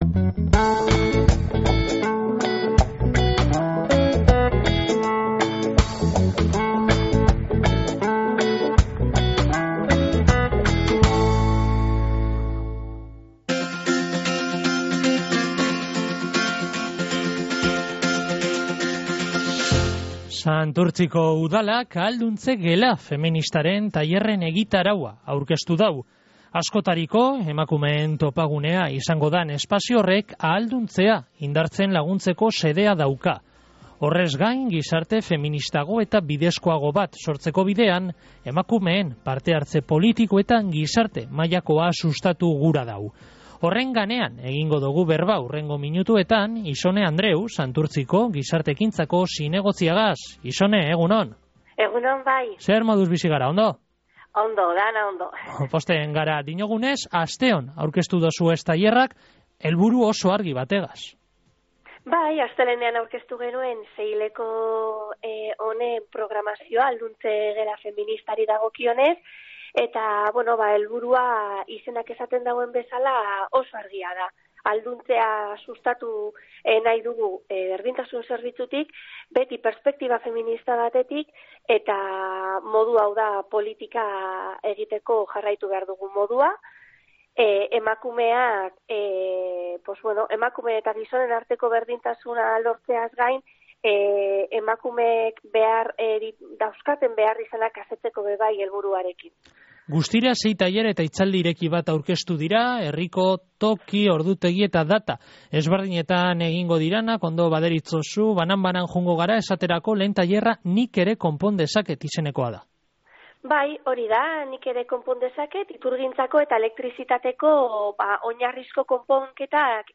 Espazioak ahalduntzea indartzen laguntzeko xedea dau, Ixone Andreu Gizarte Ekintzako zinegotziak esan deuskunez
Egitasmoaren ganeko informazino guztia emon deusku Ixone Andreu Gizarte Ekintzako zinegotziak.